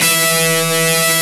Index of /90_sSampleCDs/Roland L-CD701/GTR_Distorted 1/GTR_Power Chords